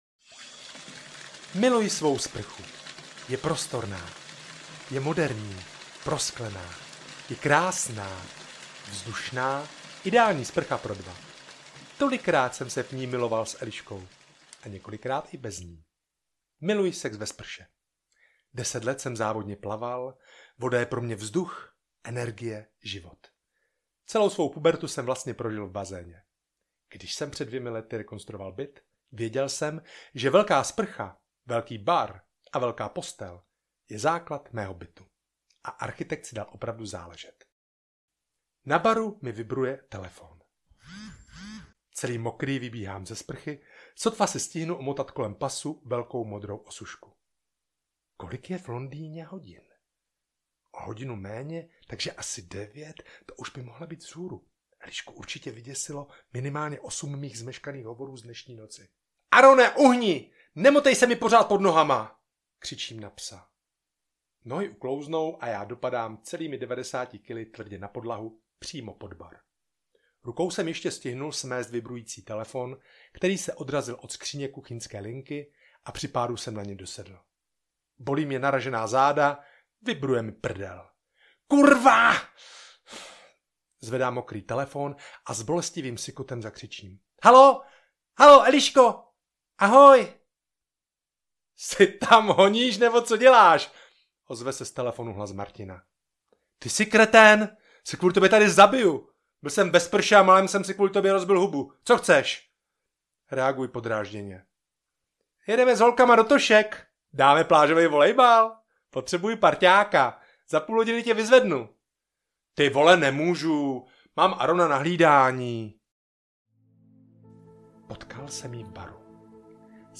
Život je boží audiokniha
Ukázka z knihy
Nahráno ve studiu Dvě mouchy v roce 2023